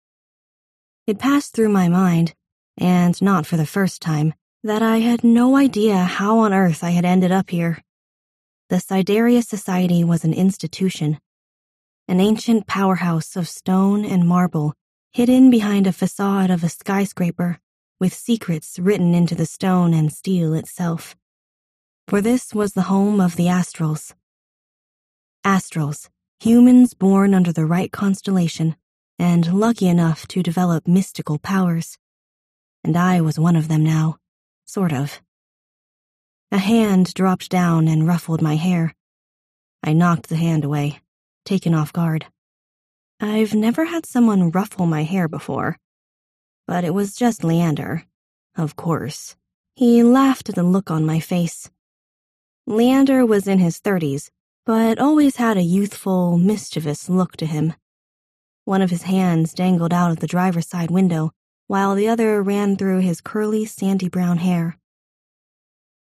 P)2022 Recorded Books Genre : Audiobook Description